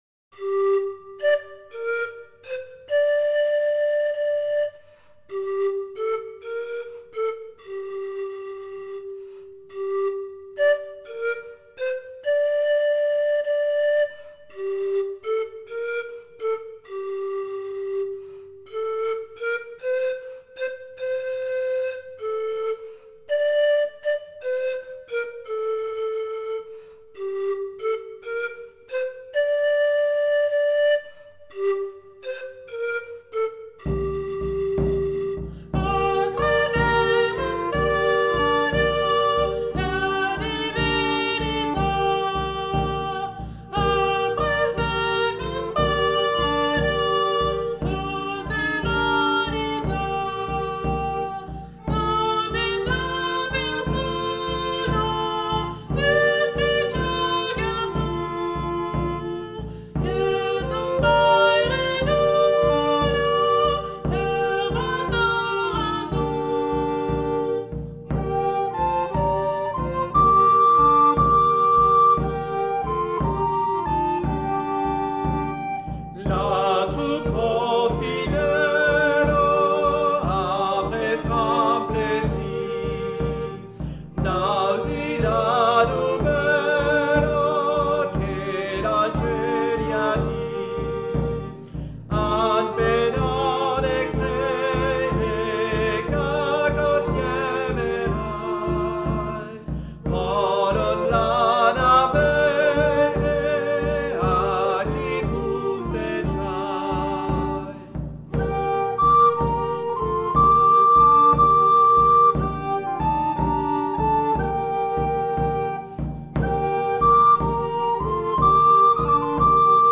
Provençal
pastre-dei-mountagno-ensemble-jehan-de-channey.mp3